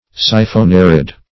Search Result for " siphonarid" : The Collaborative International Dictionary of English v.0.48: Siphonarid \Si`pho*na"rid\, n. (Zool.)